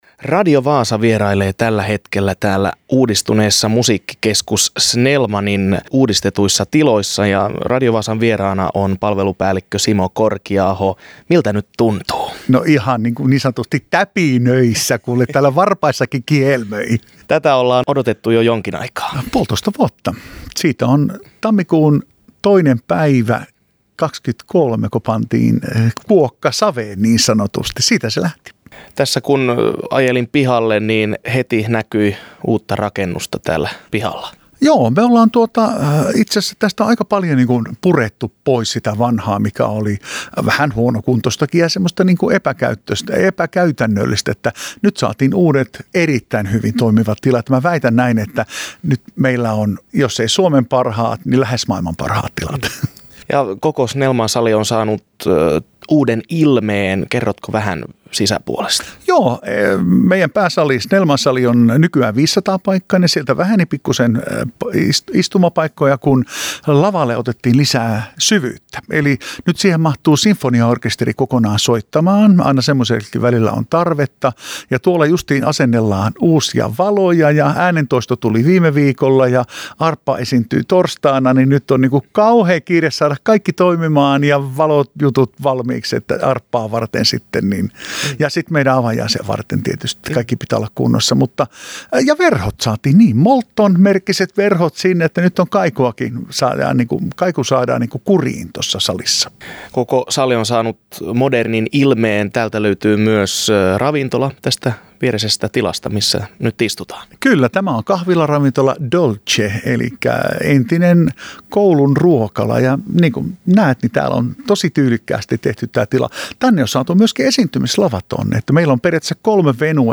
Radio Vaasa vieraili Kokkolan uudistetussa Musiikkikeskus Snellmanissa.